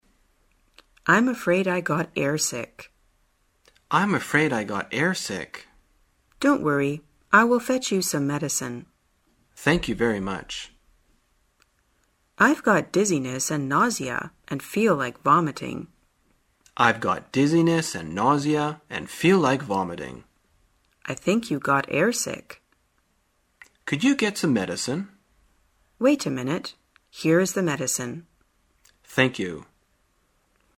在线英语听力室生活口语天天说 第117期:怎样表达晕机的听力文件下载,《生活口语天天说》栏目将日常生活中最常用到的口语句型进行收集和重点讲解。真人发音配字幕帮助英语爱好者们练习听力并进行口语跟读。